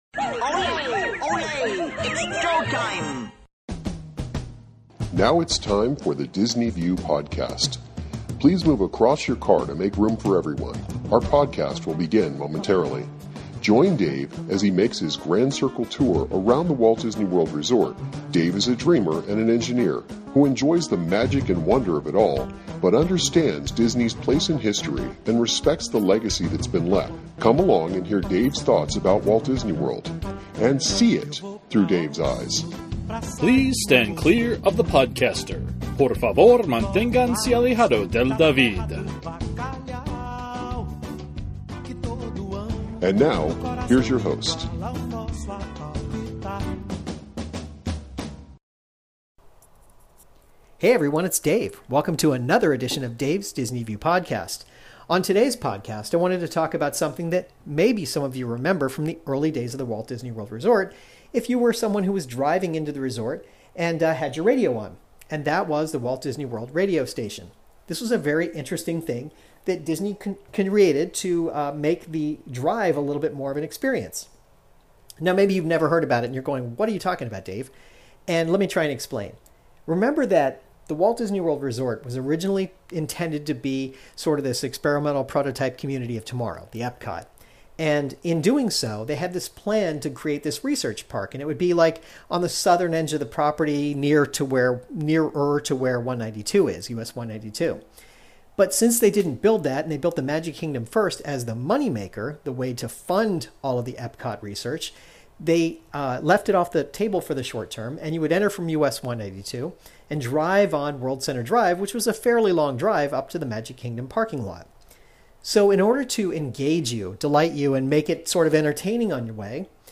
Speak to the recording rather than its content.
For the first 25 years the Disney World Resort was open, they had an AM radio station (several actually) that broadcast information about the resort - from park hours to special events to things to do. I talk about the broadcast, and play some clips for you.